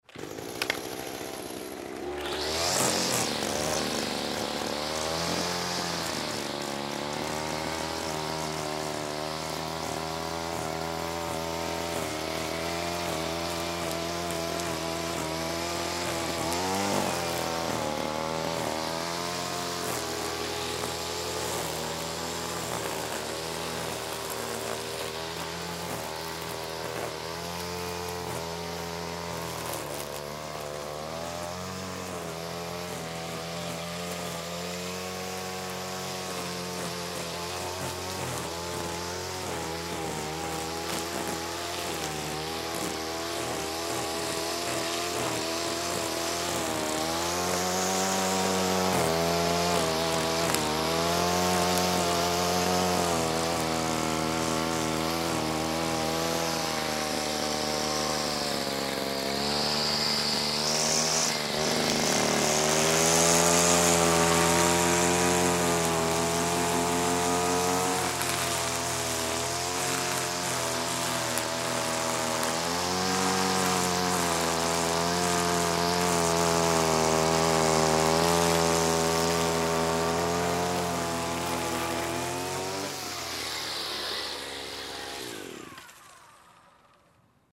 Mowing the grass | Sound effect .mp3 | Download free.
Mowing the grass: